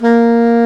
SAX SOPMFA0D.wav